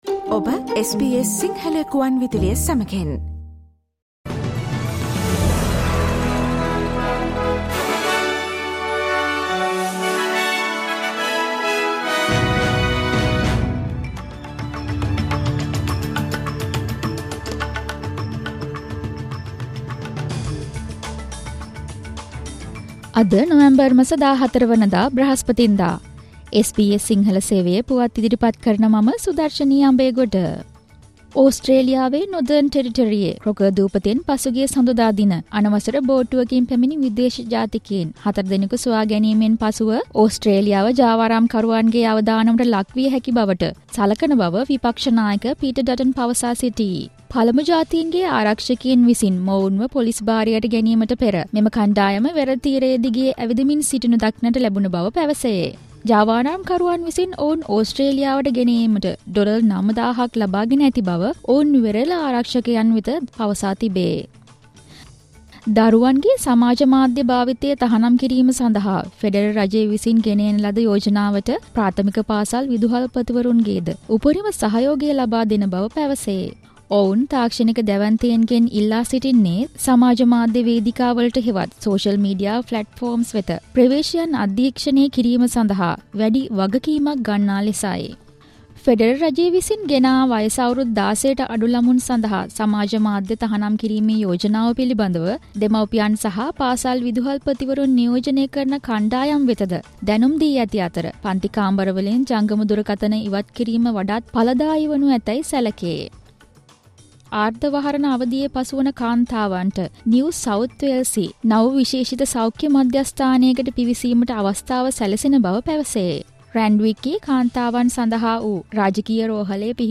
Australian news in Sinhala, foreign and sports news in brief. Listen, Thursday 14 November SBS Sinhala radio news flash